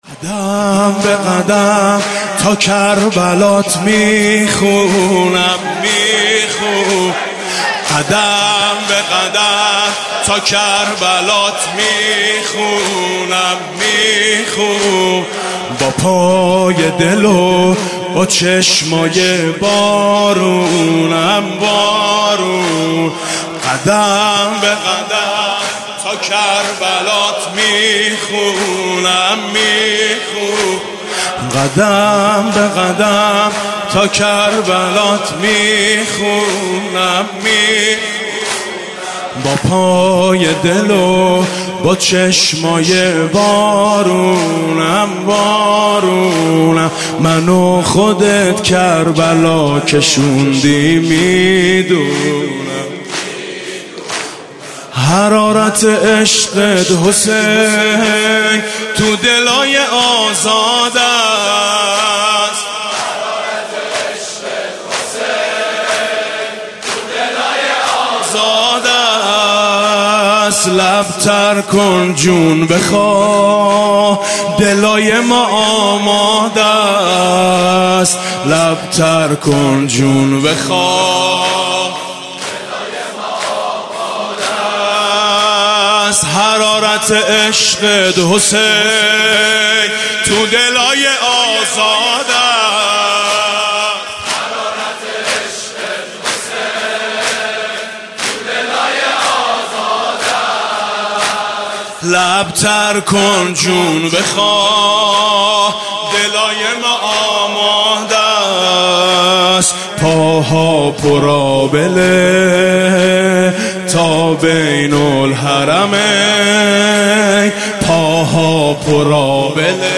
زمینه: منو خودت کربلا کشوندی میدونم